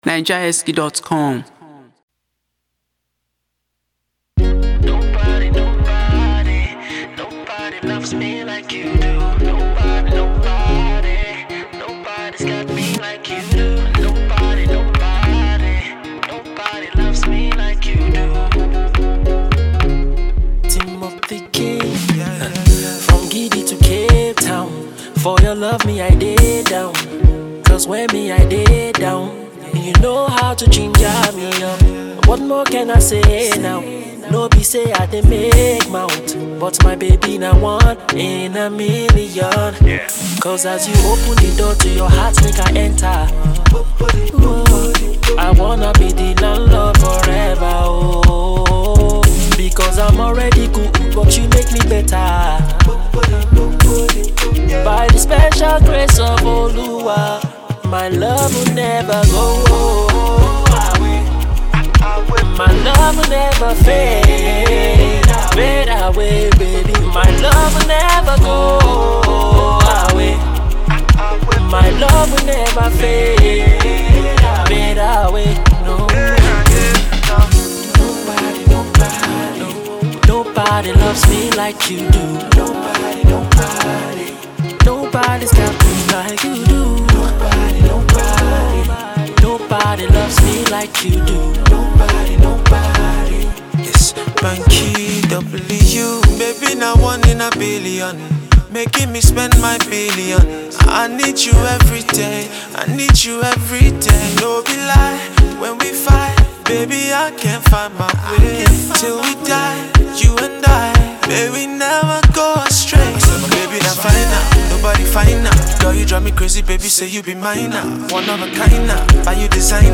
a fusion of R&B with Afrosoul
his sonorous voice